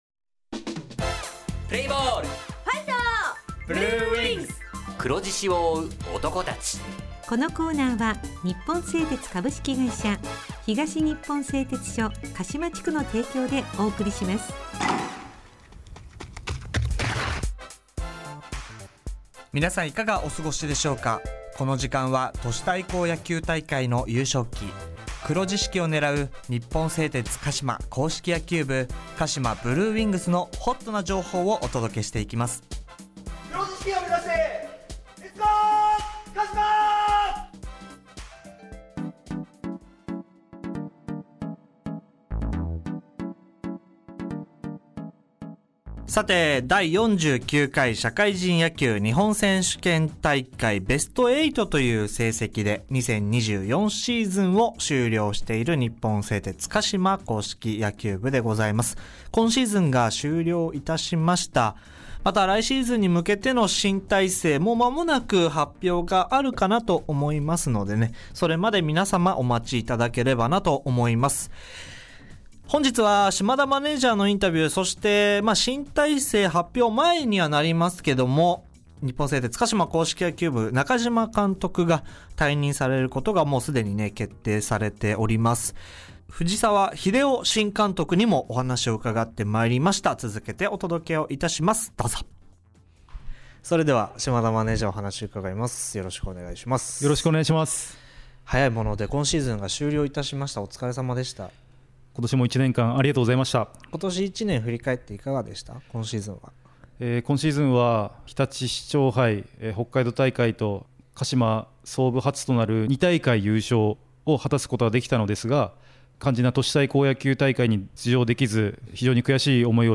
地元ＦＭ放送局「エフエムかしま」にて鹿島硬式野球部の番組放送しています。